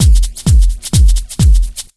129BEAT1 5-R.wav